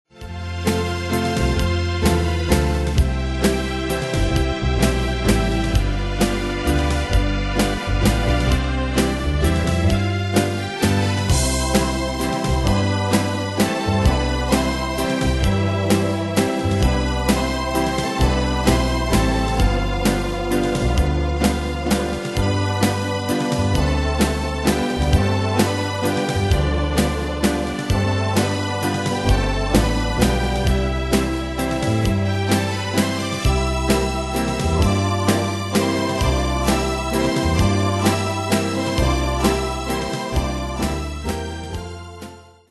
Demos Midi Audio
Danse/Dance: Valse/Waltz Cat Id.